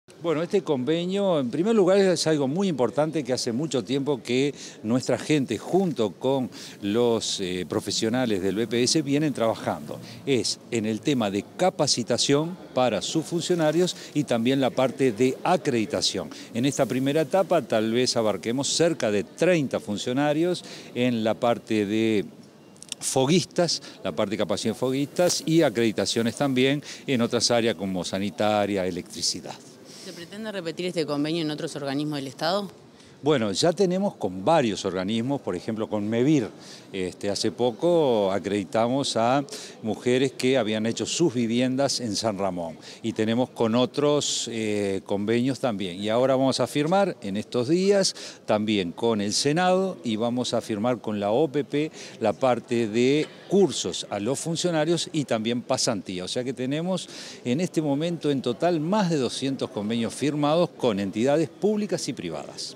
Declaraciones del director general de Educación Técnico Profesional, Juan Pereyra
Tras la firma de un convenio con el Banco de Previsión Social (BPS) para la acreditación de saberes, capacitaciones profesionales y colaboración en la selección y promoción del personal del mencionado organismo, este 29 de agosto, el director general de Educación Técnico Profesional, Juan Peyrera, realizó declaraciones a la prensa.